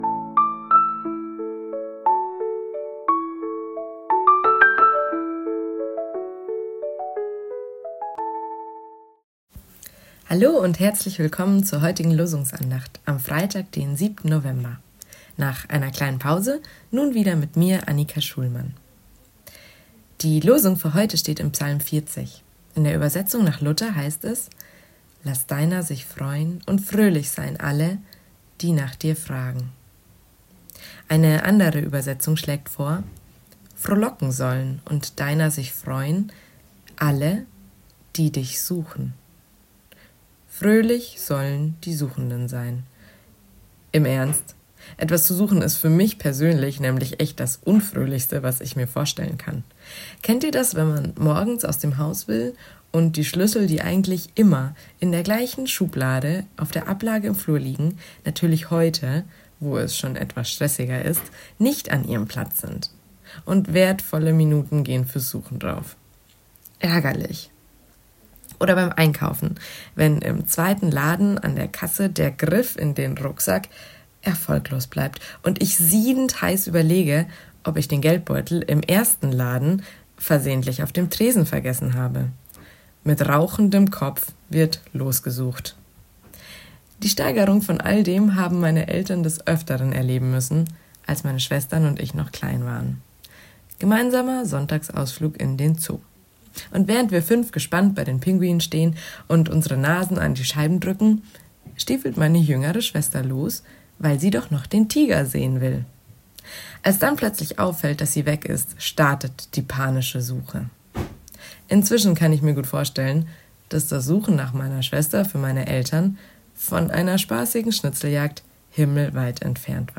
Losungsandacht für Freitag, 07.11.2025
Text und Sprecherin